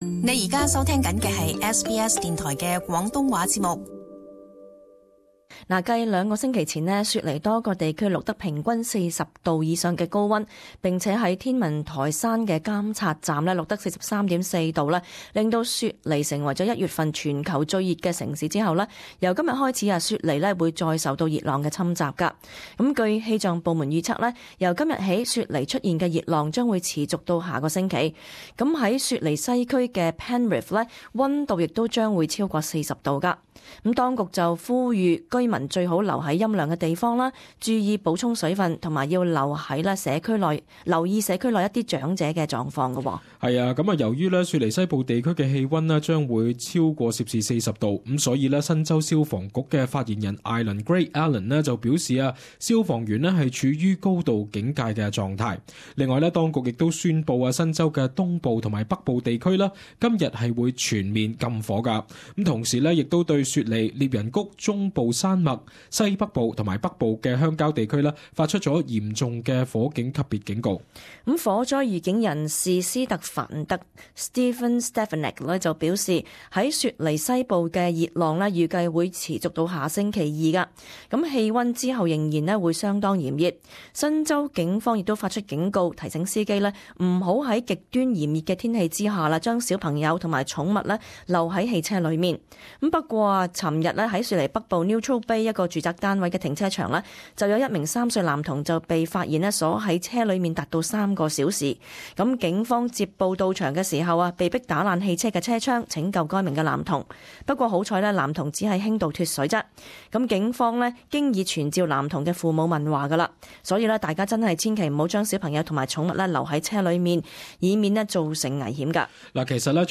【時事報導】專家籲體育場館改革以抗熱浪